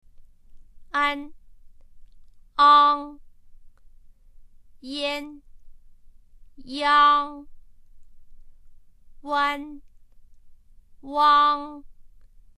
☆鼻音つき母音（びおんつきぼいん）　：
an-ang-yan-yang-wan-wang.mp3